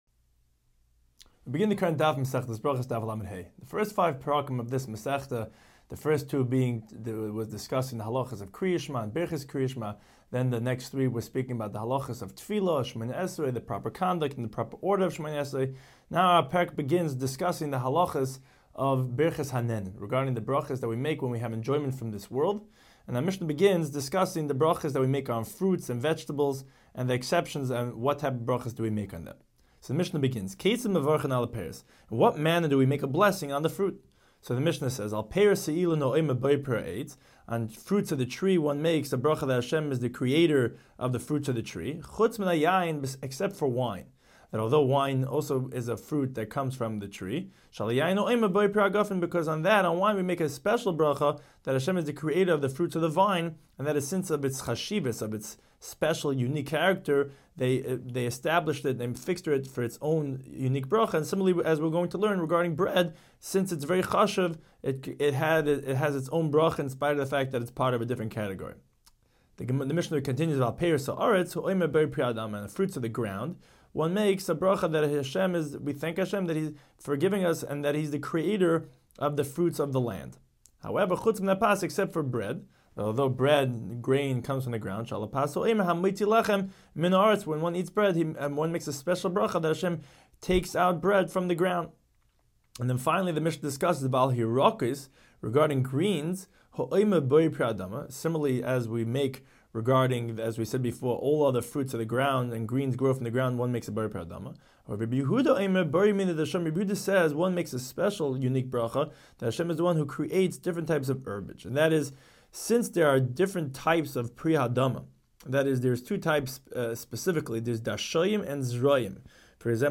Daf Hachaim Shiur for Berachos 35